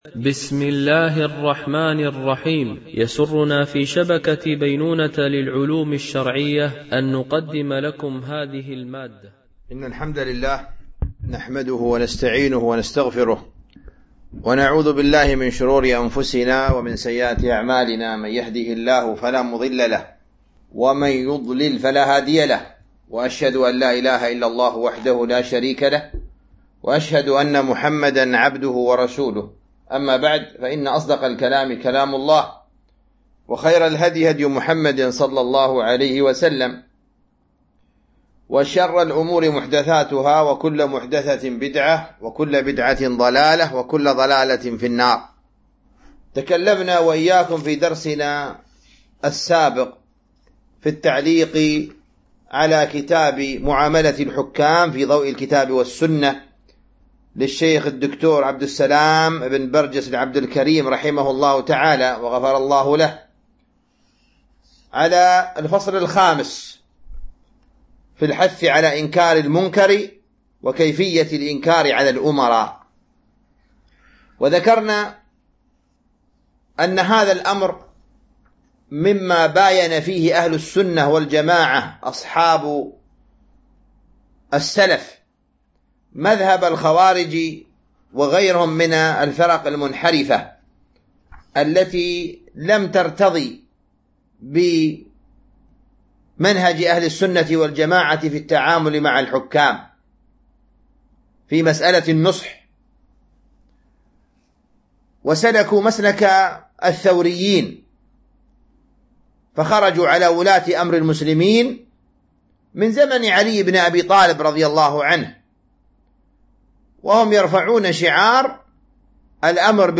التعليق على كتاب: معاملة الحكام في ضوء الكتاب والسنة - الدرس 15 (هدي السلف في نصح الحاكم)
MP3 Mono 22kHz 32Kbps (CBR)